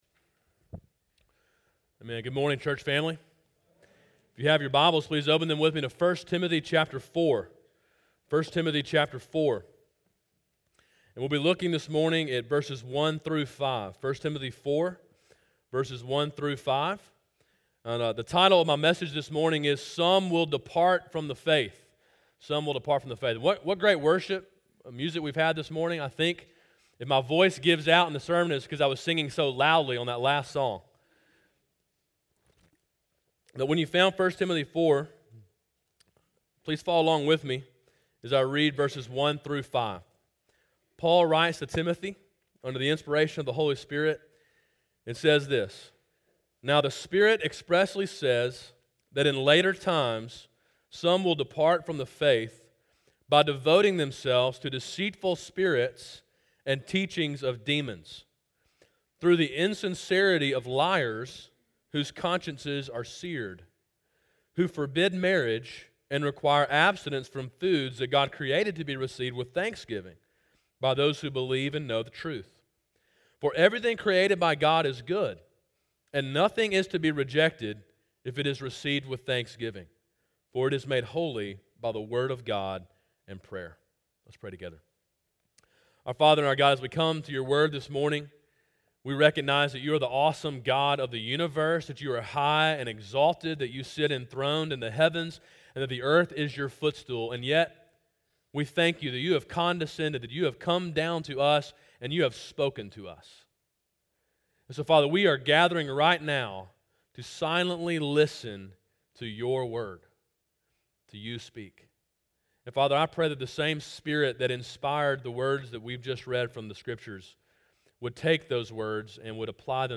A sermon in a series on the book of 1 Timothy.